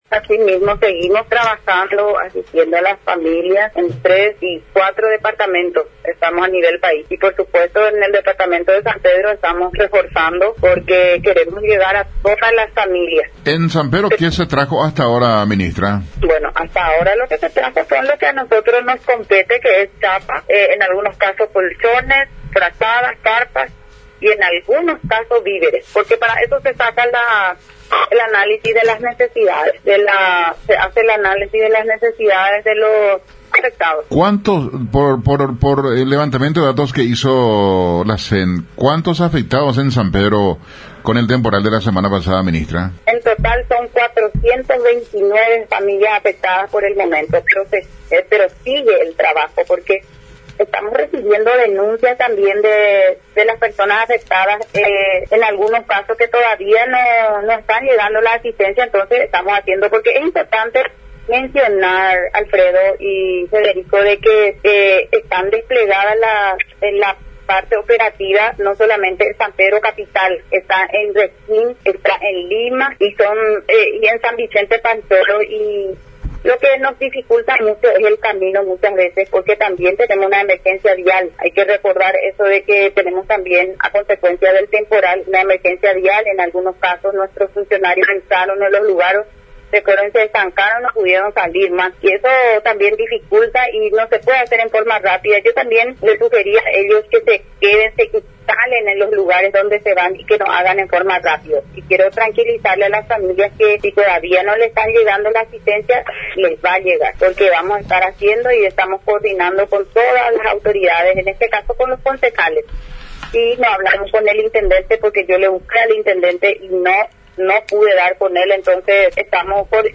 La titular de la SEN, Gladys Zunilda Borja, en contacto con Radio Nacional, valoró las tareas desplegadas por la institución a su cargo, donde algunos de los funcionarios se encuentran abocados en la asistencia, mientras otro grupo se encarga de levantar informes de los afectados.